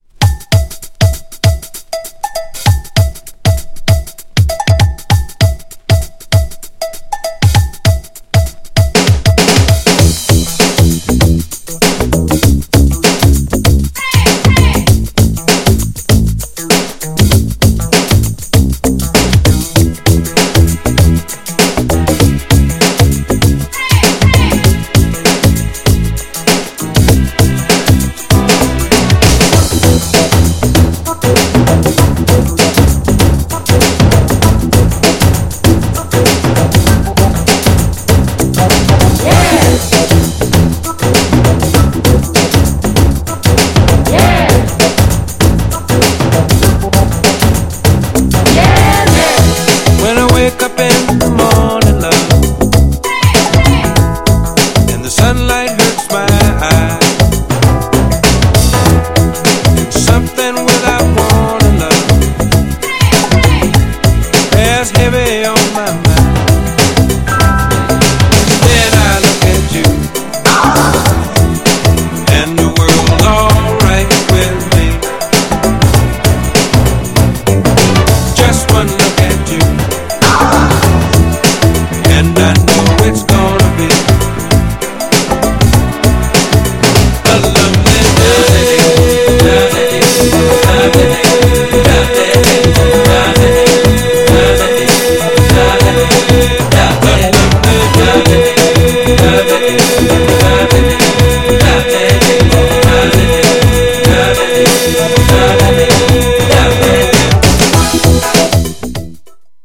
GENRE Dance Classic
BPM 111〜115BPM